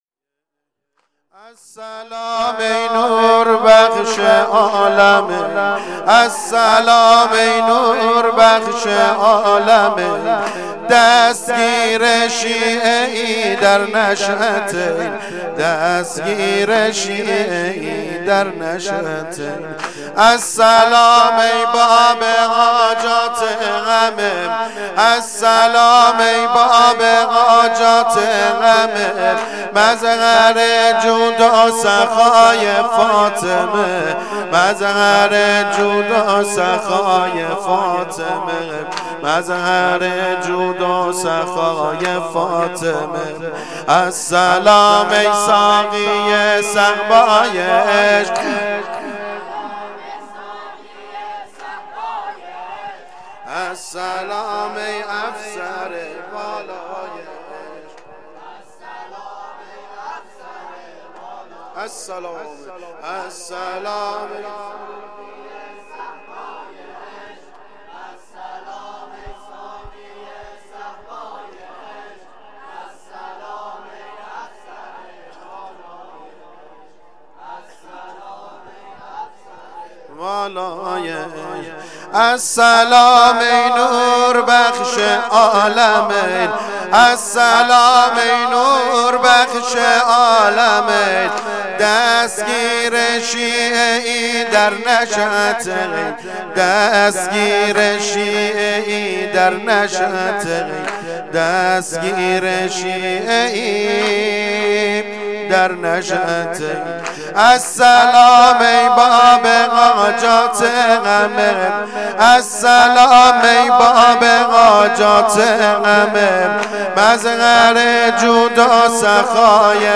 واحد | السلام ای نور بخش عالمین
جلسه هفتگی 96/09/29
هیئت طفلان حضرت مسلم (ع) شهر اختیارآباد